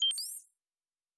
Additional Weapon Sounds 1_3.wav